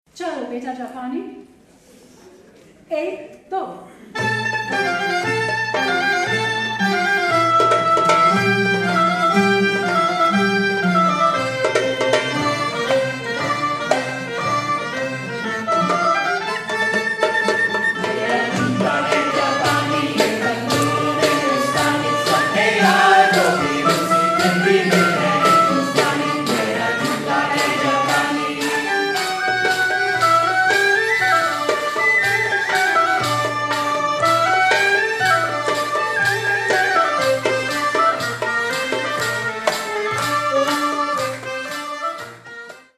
Live in India! CD